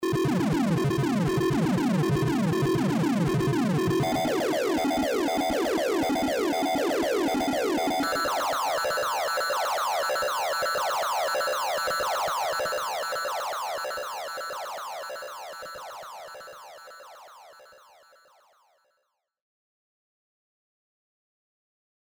オシレータ3のみをオンにした状態で同じフレーズを演奏させた例です。
Spire_soundmake_demo_013_osc3.mp3